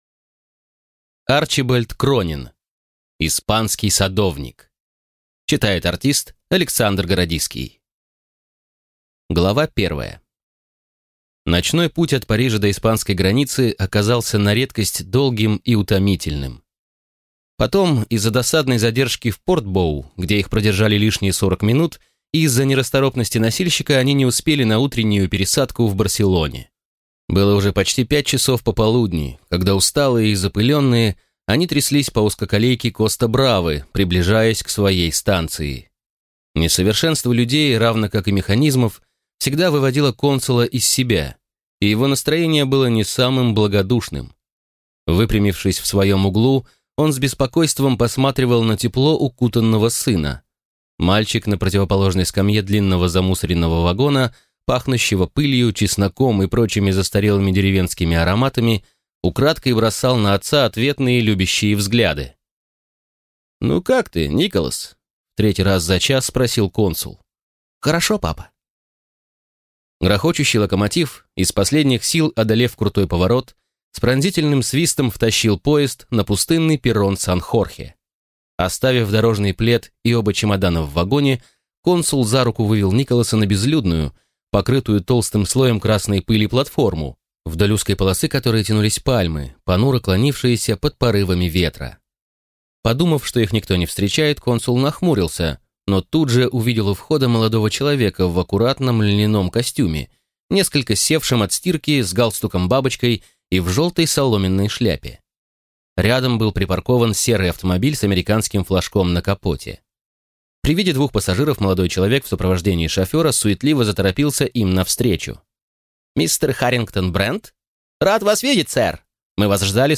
Аудиокнига Испанский садовник | Библиотека аудиокниг
Прослушать и бесплатно скачать фрагмент аудиокниги